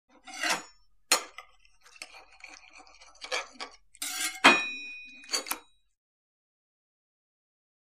Cutting, Meat | Sneak On The Lot
Cutting Food On Plate With Light Shing And Meat Plop